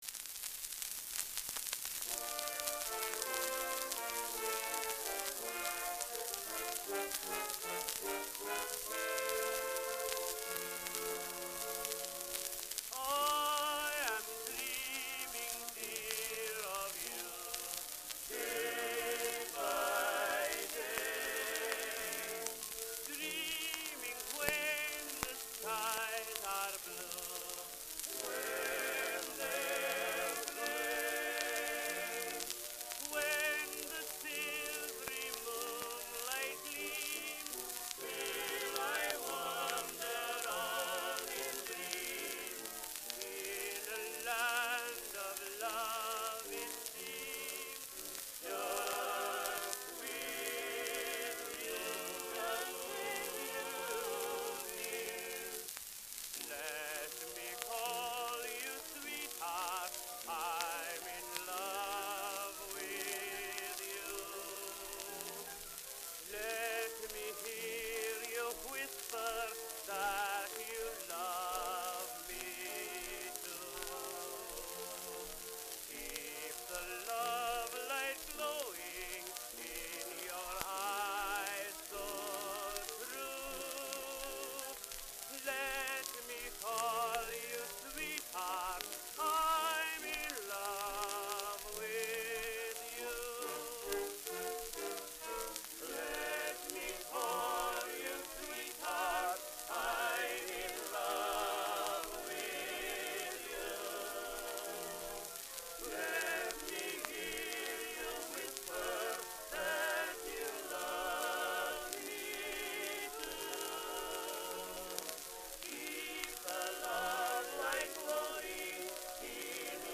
Complete Recording (78rpm) *#825173
Performers Columbia male vocal quartet with orchestra Copyright Public Domain [ tag / del ] Misc.